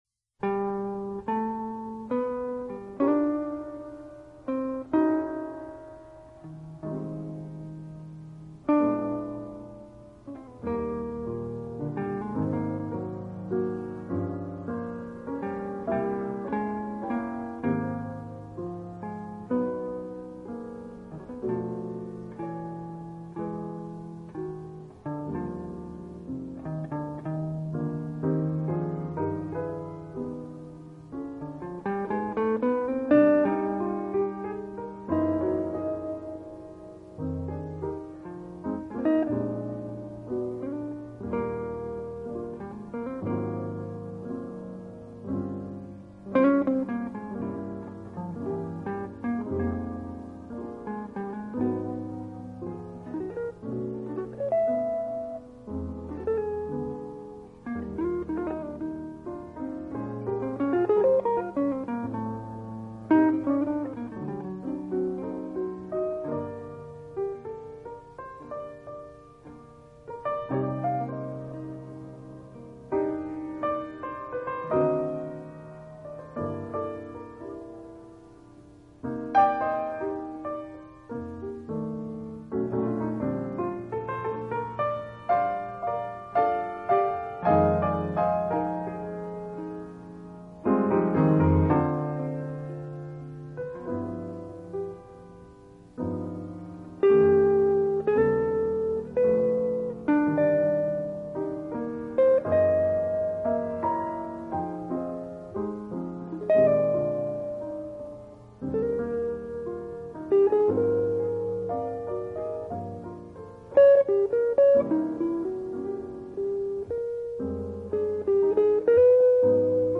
音乐类型：Jazz
主要演奏乐器：钢琴
主要音乐风格：后波普爵士乐、冷爵士乐